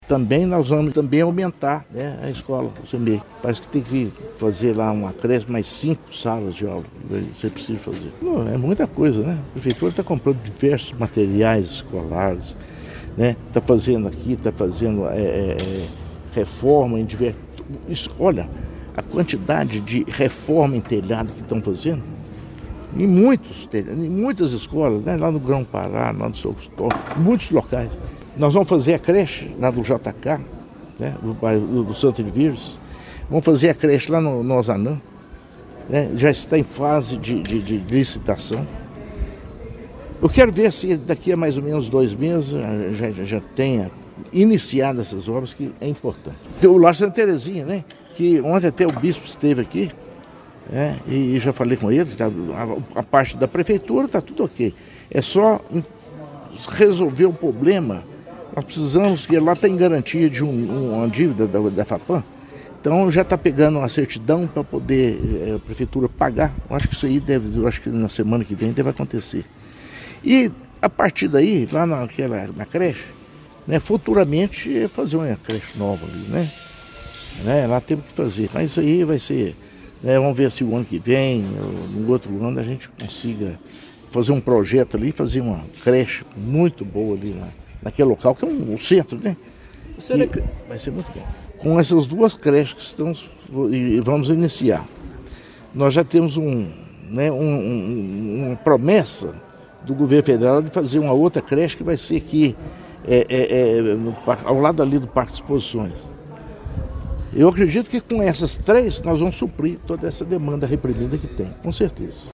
O prefeito Inácio Franco também falou sobre outros investimentos em creches com o objetivo de zerar a fila de espera por vagas: